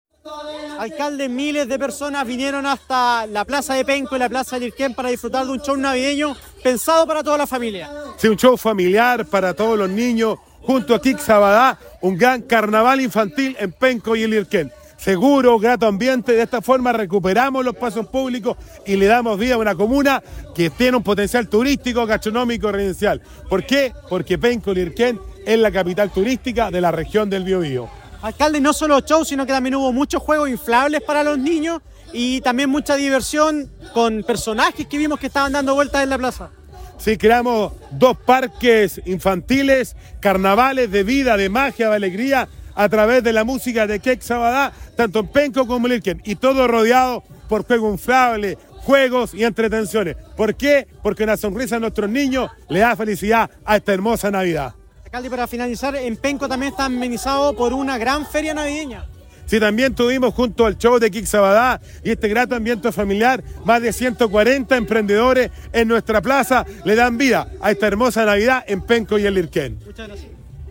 CUÑA_ALCALDE_NAVIDAD
CUNA_ALCALDE_NAVIDAD.mp3